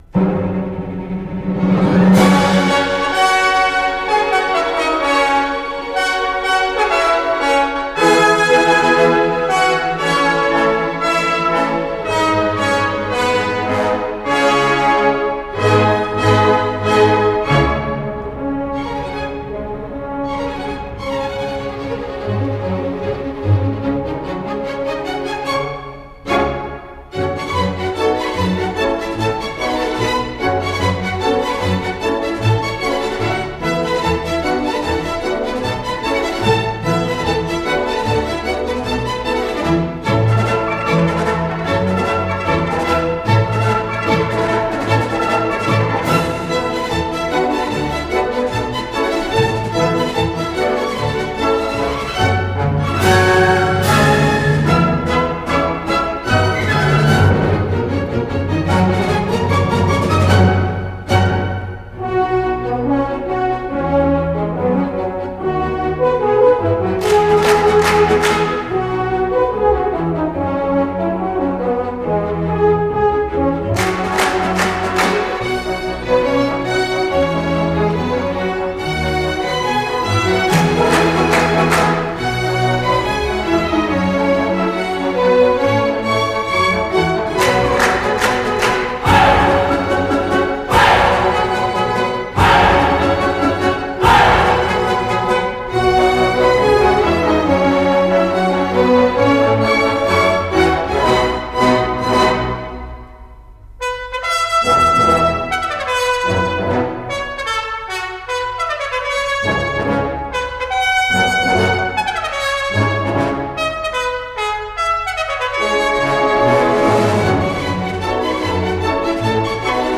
1967珍稀版  钢琴伴唱